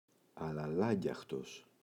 αλαλάγγιαχτος [alaꞋlaɟaxtos] – ΔΠΗ